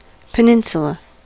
Ääntäminen
Vaihtoehtoiset kirjoitusmuodot (harvinainen) pæninsula Synonyymit ness headland chersonese byland half-island cape Ääntäminen US UK : IPA : /pəˈnɪn.sjə.lə/ IPA : /ˈpɛn.ɪn.sjʊ.lə/ US : IPA : /pəˈnɪn.sə.lə/